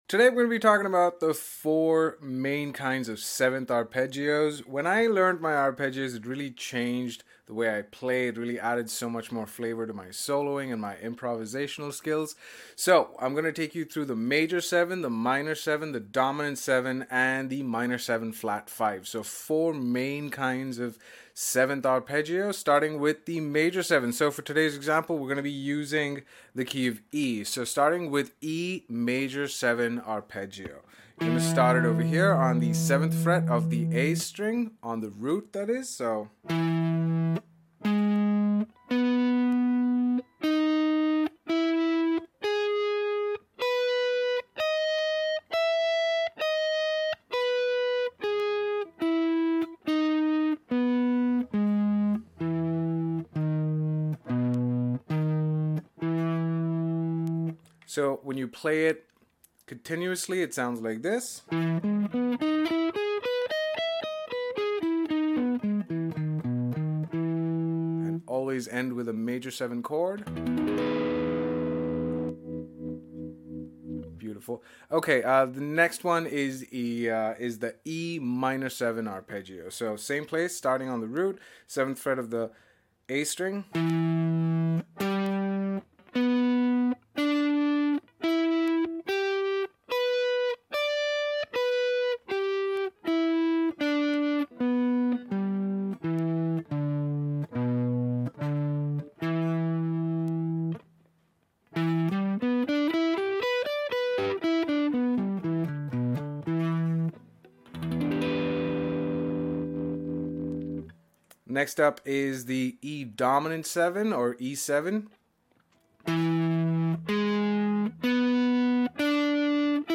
4 Types Of 7th Arpeggios Sound Effects Free Download